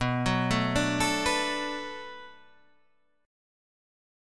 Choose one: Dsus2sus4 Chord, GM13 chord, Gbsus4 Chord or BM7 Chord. BM7 Chord